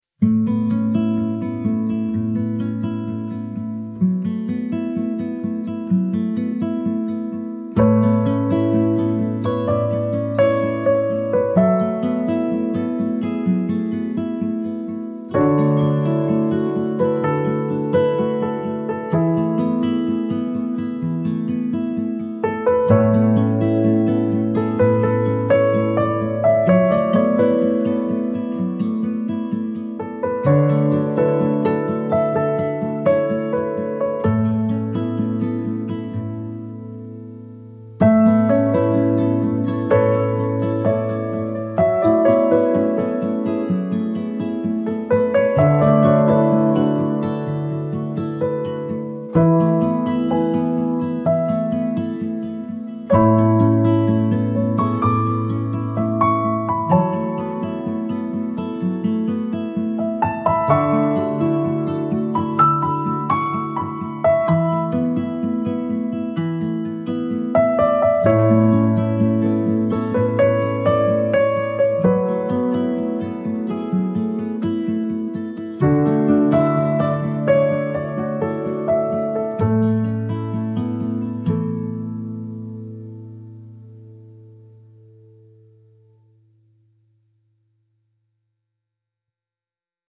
soft acoustic guitar and piano duet evoking cherished memories